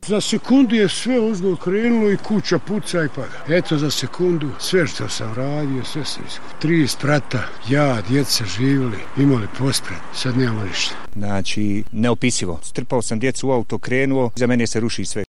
Mještani naselja Svrake